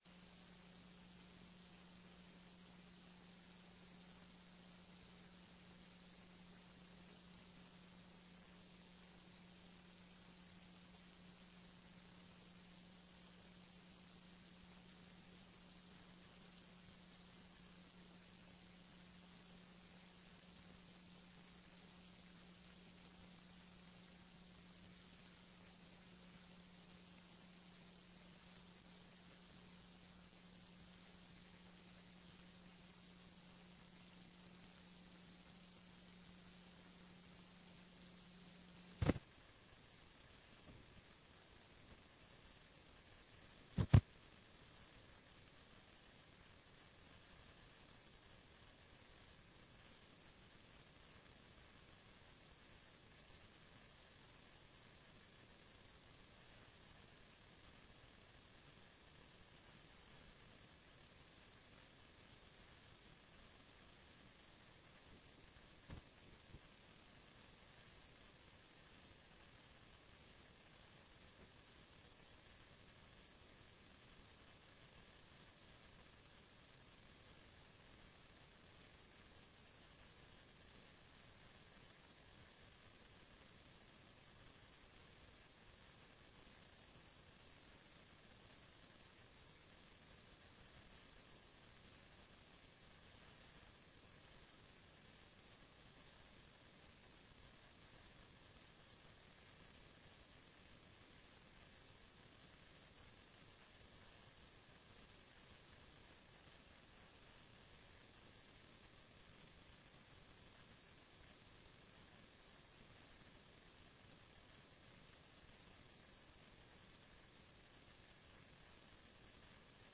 TRE-ES - áudio da sessão 14.11